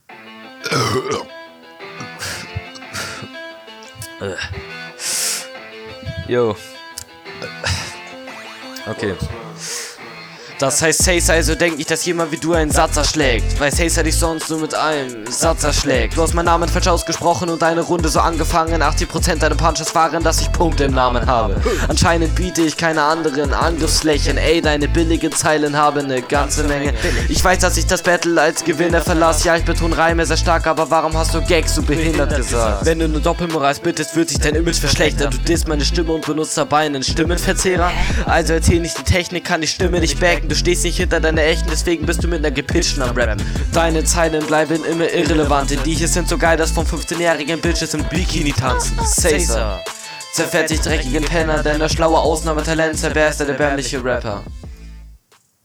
Soundquali ausbaubar aber anhörbarer da kein pitch. Flow / betonungen leichte unreinheiten.